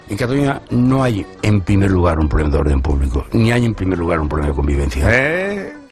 Declaraciones de Rafael Ribó